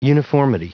Prononciation du mot uniformity en anglais (fichier audio)
Prononciation du mot : uniformity